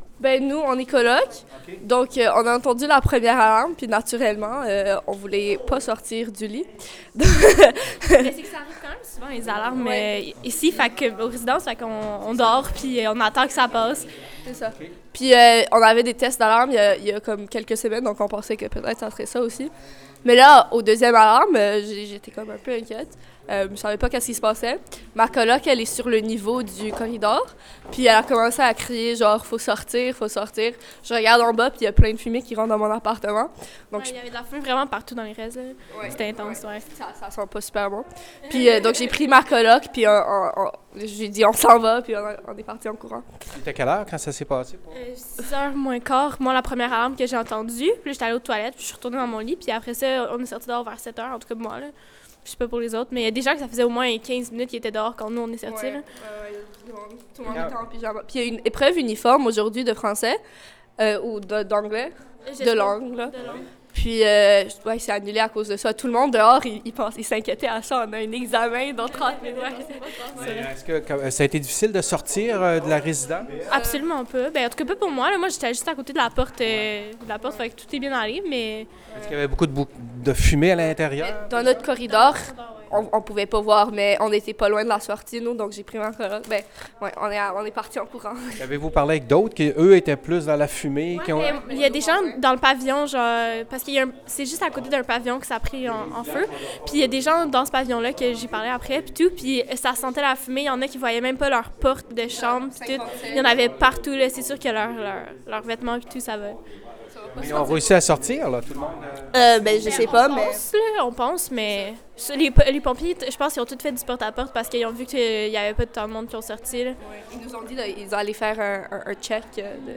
Témoignages d’étudiants qui ont été évacués des résidences le matin de l’incendie: